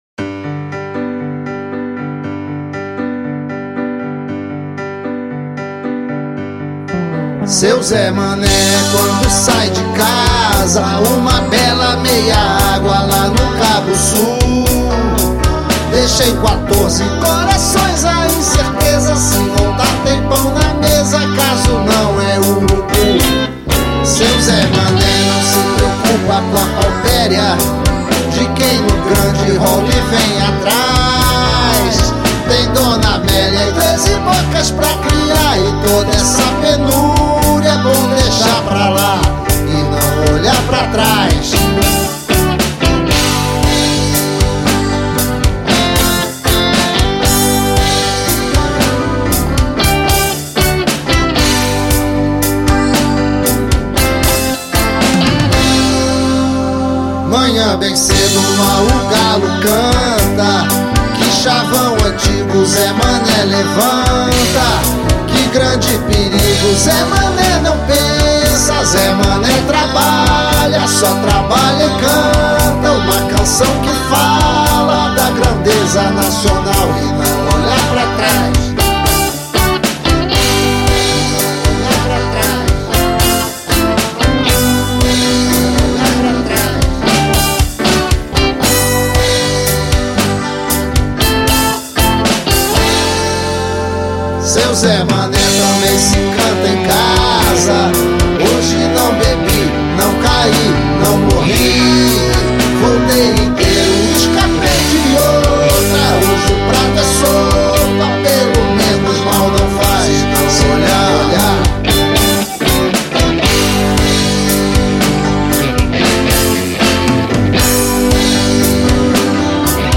EstiloRock Progressivo